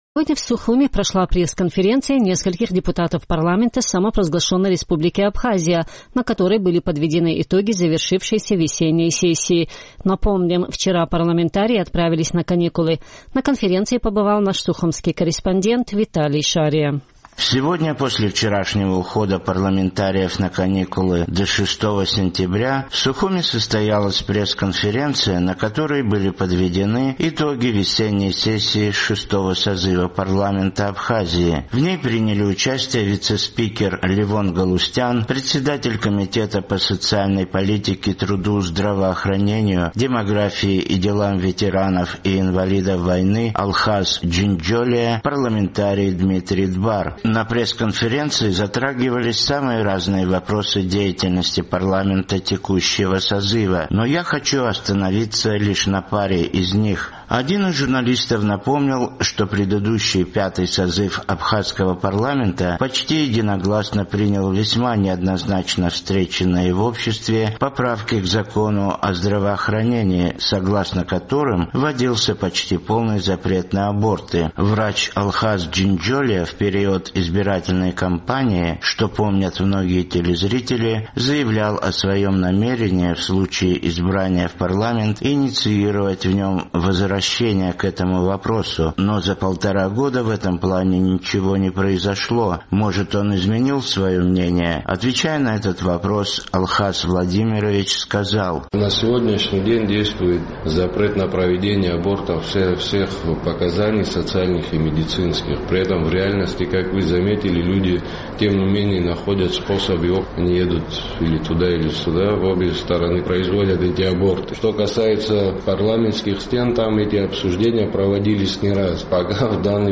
Сегодня в Сухуме прошла пресс-конференция нескольких депутатов парламента Абхазии, на которой были подведены итоги завершившейся весенней сессии.